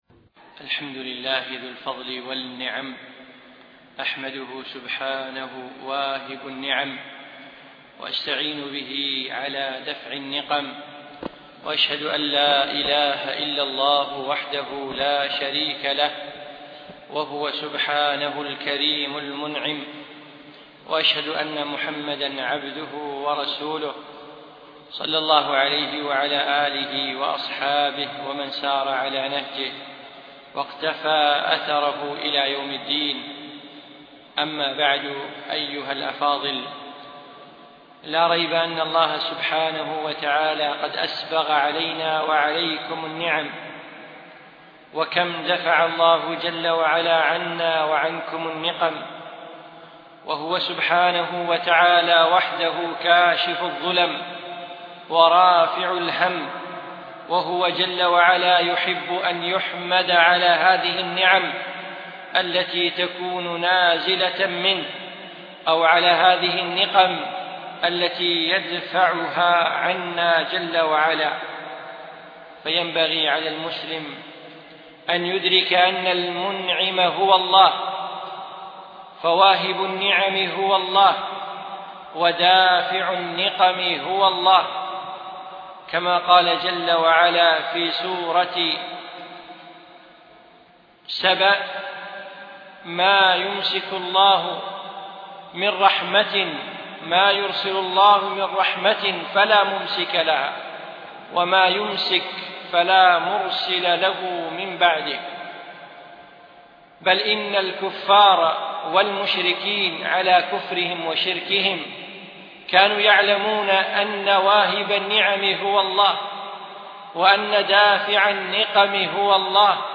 أقيمت المحاضرة في دولة الإمارات